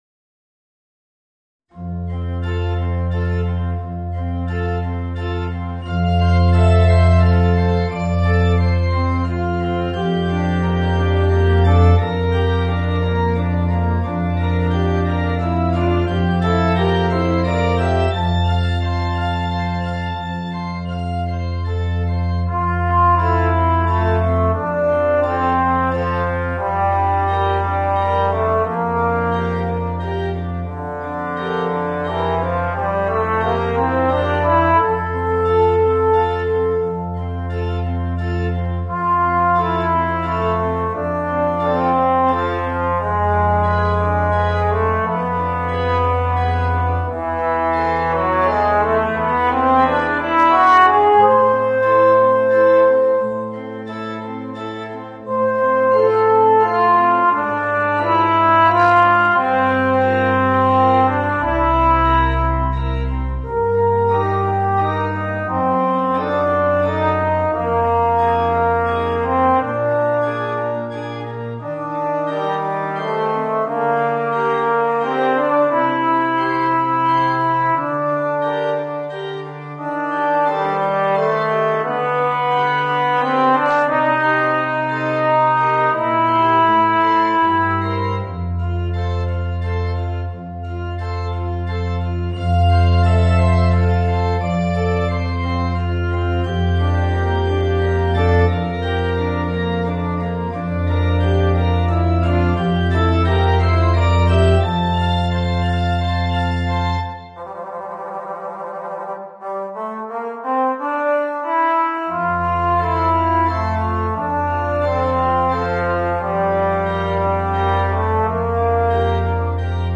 Voicing: Alto Trombone and Piano